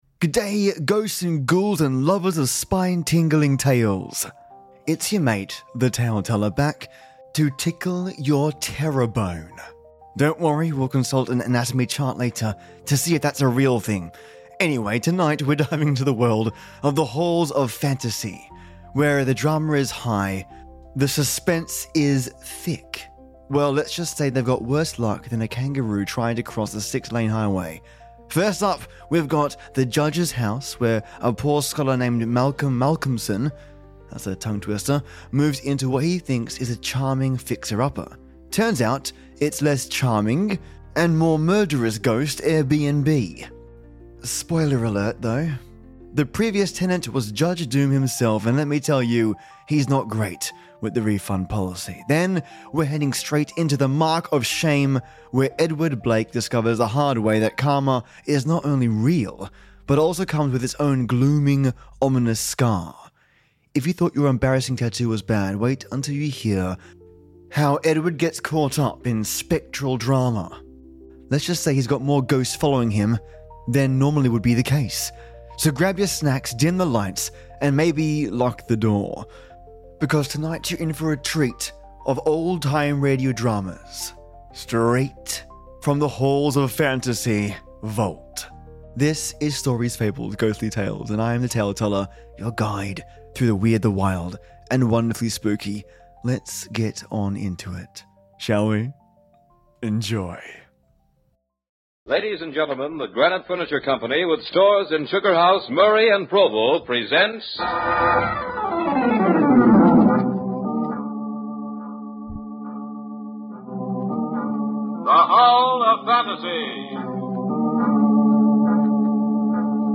The Halls of Fantasy was a spine-chilling old-time radio series that aired from 1946 to 1952. Produced during the golden age of radio, the show delivered eerie and suspenseful tales that combined supernatural elements, psychological thrills, and moral dilemmas. While less well-known than radio giants like Suspense or The Inner Sanctum, The Halls of Fantasy carved out its niche by exploring deeply atmospheric storytelling, filled with haunting soundscapes and memorable characters.